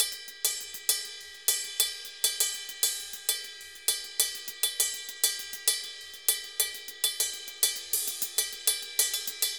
Ride_Candombe 100_1.wav